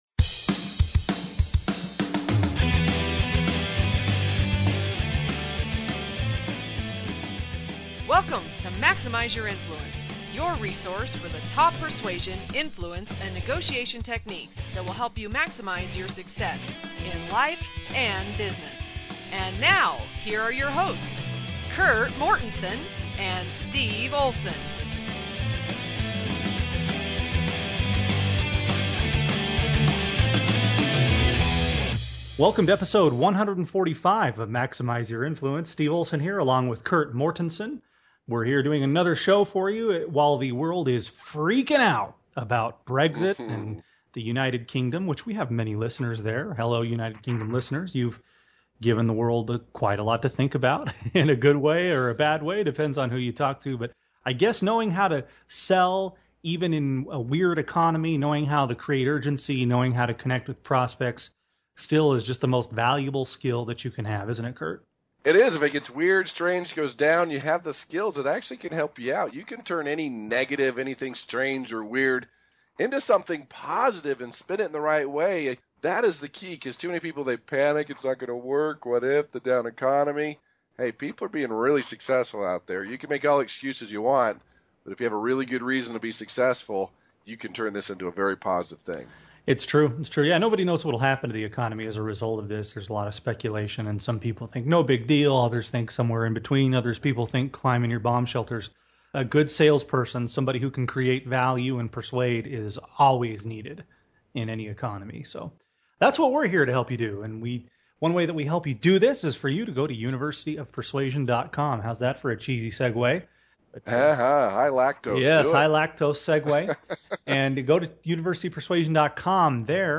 Episode 145 – Interview